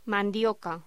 Locución: Mandioca
voz